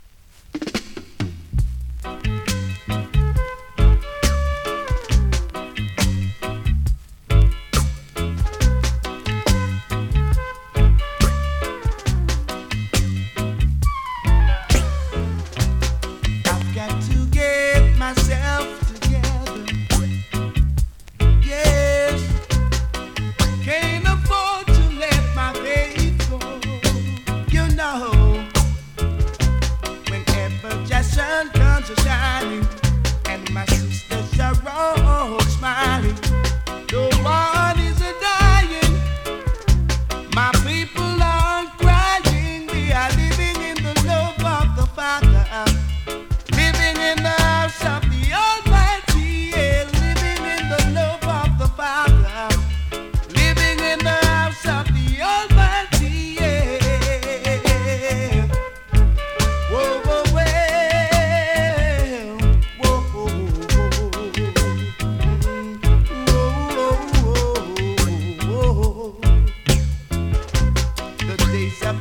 ホーム > 2024 NEW IN!! DANCEHALL!!
スリキズ、ノイズ比較的少なめで